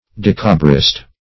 Dekabrist \Dek"a*brist\, n.